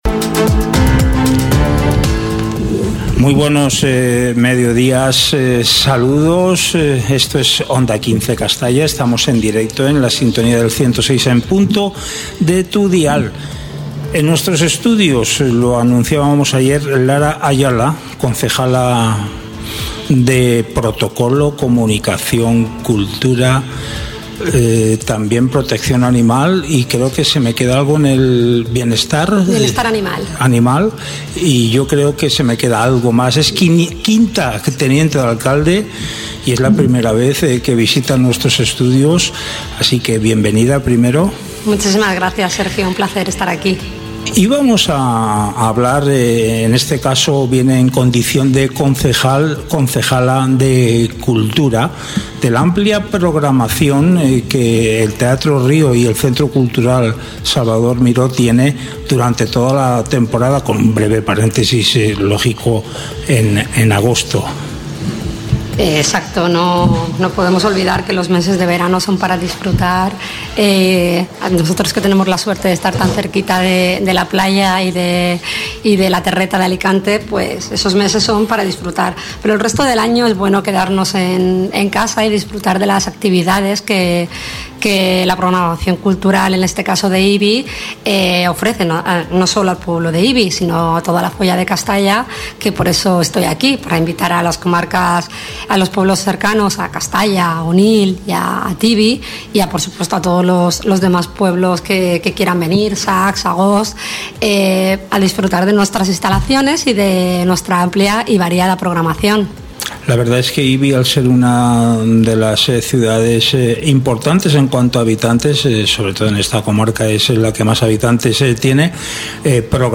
Entrevista a Lara Ayala, concejala de cultura, bienestar animal, participación ciudadana y comunicación del Excmo. Ayuntamiento de Ibi - Onda 15 Castalla 106.0 FM
Hoy en nuestro Informativo, contamos con la presencia de Lara Ayala, concejala de Cultura, Bienestar Animal, Participación Ciudadana y Comunicación del Excmo. Ayuntamiento de Ibi.
En esta entrevista, repasamos la variada programación prevista para este trimestre en dos de los principales espacios culturales de la localidad: el Teatro Río y el Centre Cultural Salvador Miró.